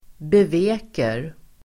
Uttal: [bev'e:kar]